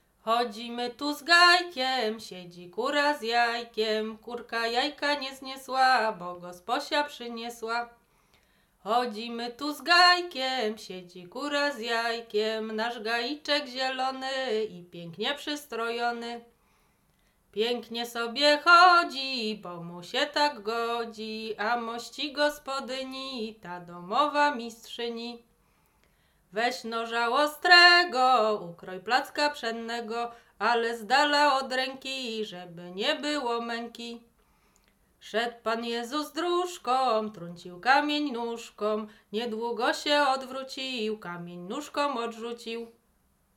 Łęczyckie
dyngusowe dyngus kolędowanie wiosenne maik wielkanoc wiosenne wiosna